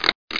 00678_Sound_switch.mp3